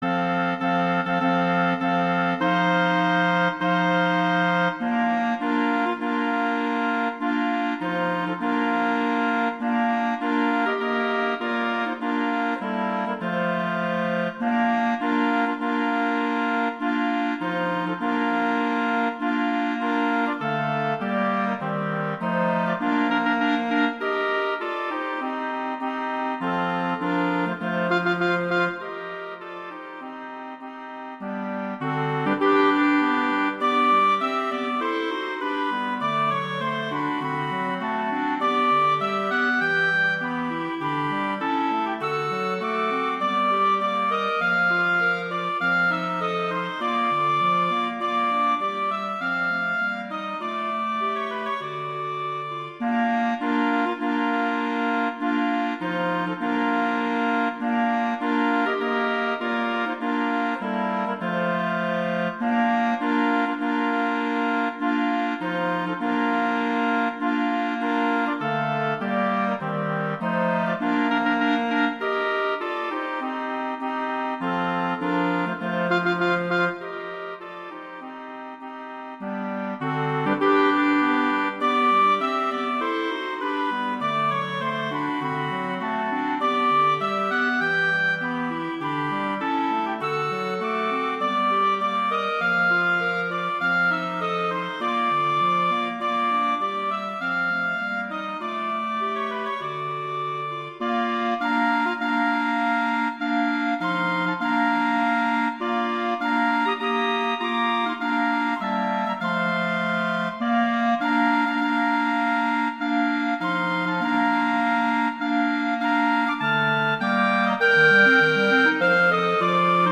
Instrumentation: wind quartet
arrangements for wind quartet
for flute, oboe and two clarinets in Bb.
wedding, traditional, classical, festival, love, french